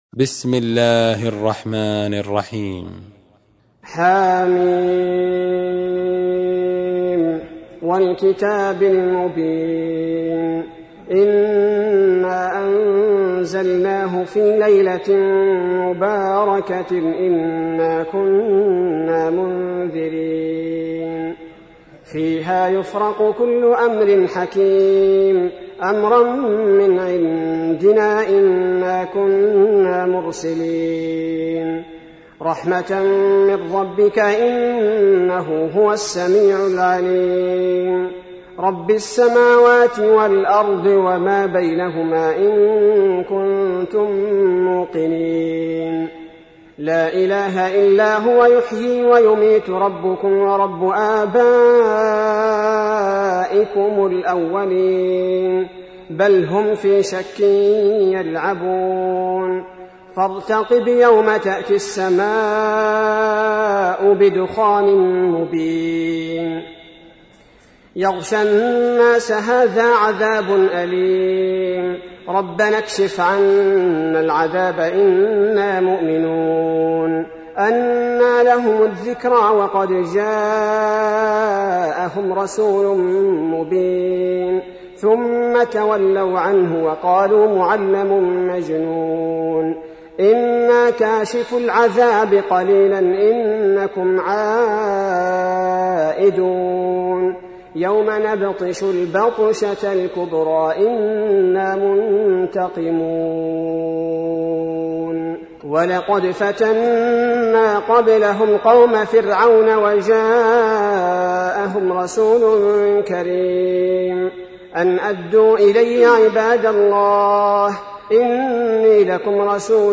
تحميل : 44. سورة الدخان / القارئ عبد البارئ الثبيتي / القرآن الكريم / موقع يا حسين